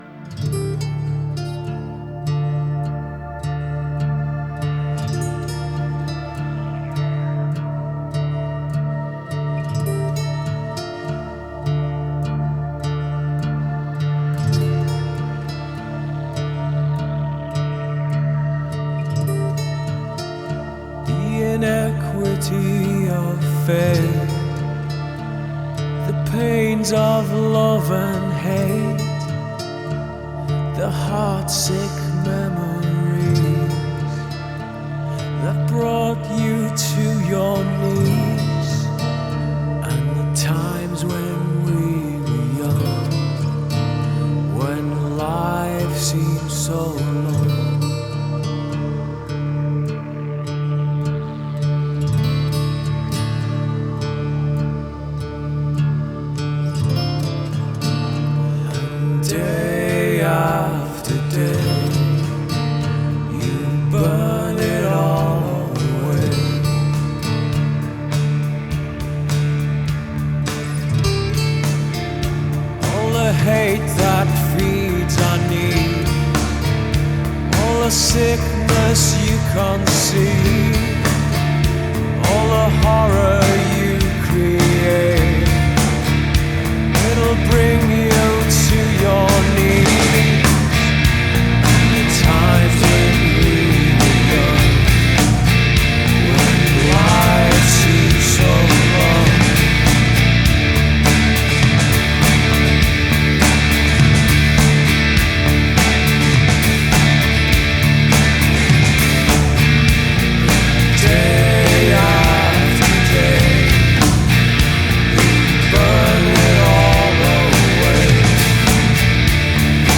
Rock راک